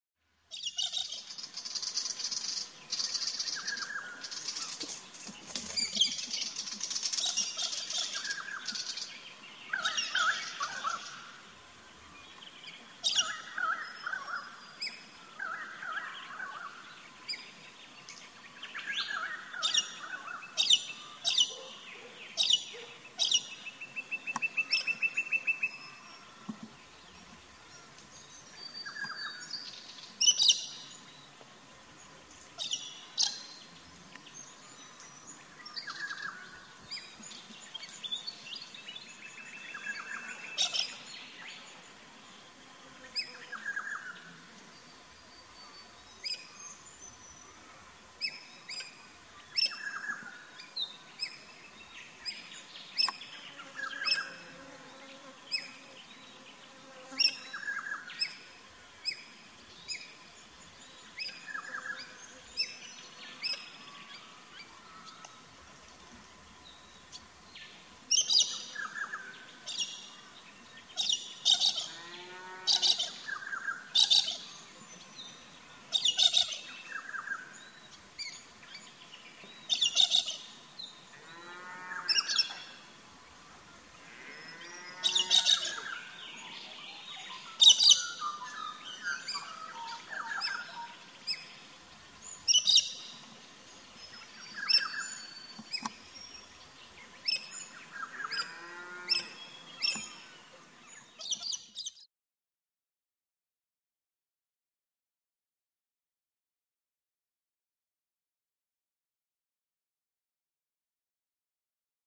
38Bird-CallsAustralian-GalahBackyard-Birds-Singing-02.mp3